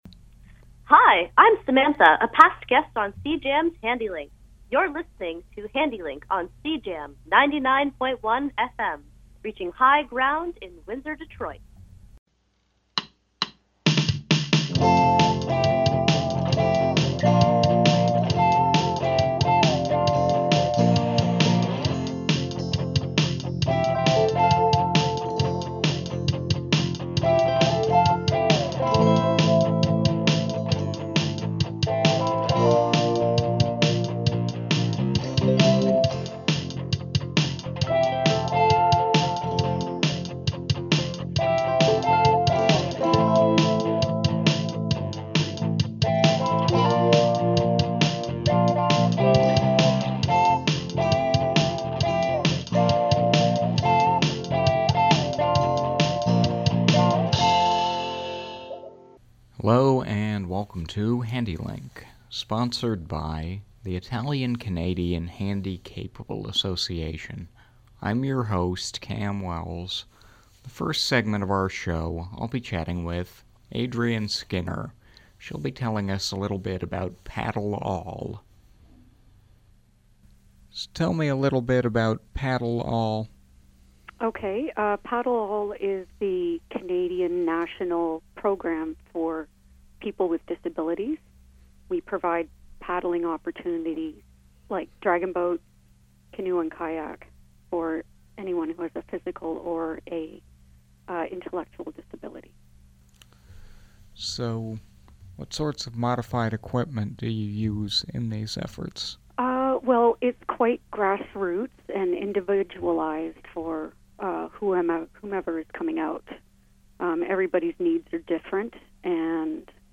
Handi-Link-_Paddle_all_aboutface_panel_.mp3